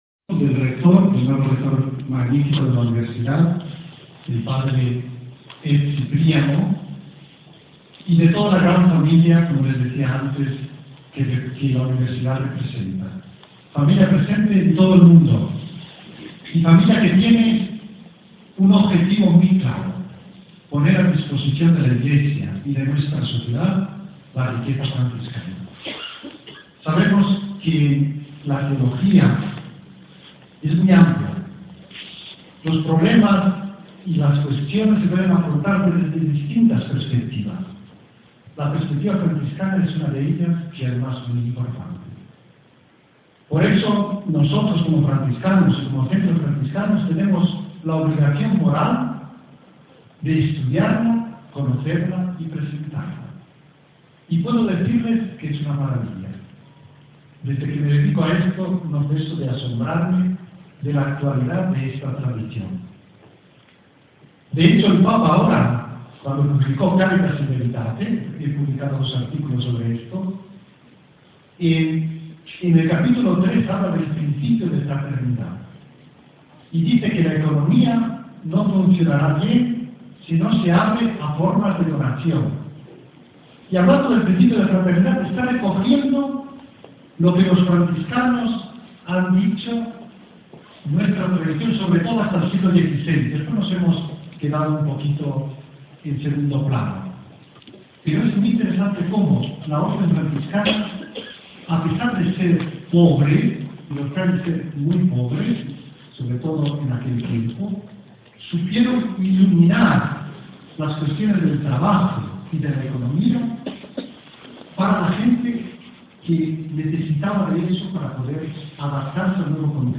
La Visita si è svolta dal 4 al 8 settembre 2011, con il seguente programma:
Dopo la conferenza, gli studenti lavorano in gruppi e, dopo una pausa, formulano delle domande al ponente